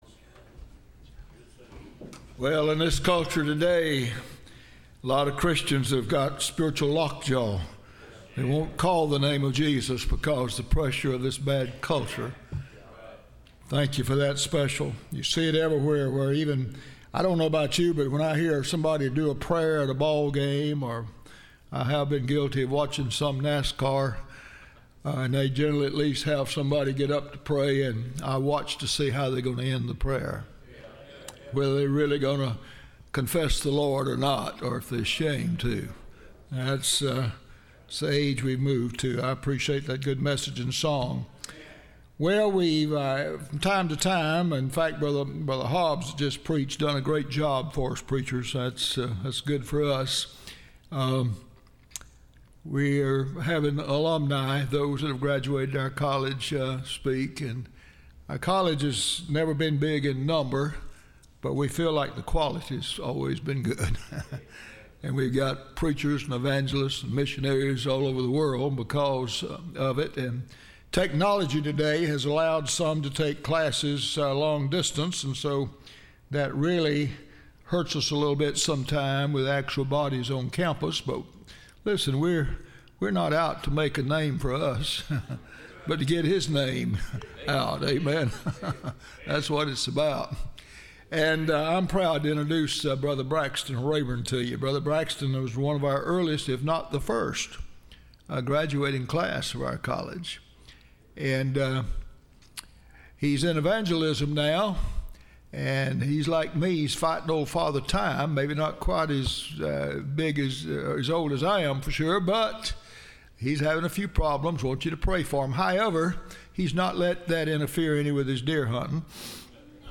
Service Type: Bible Conference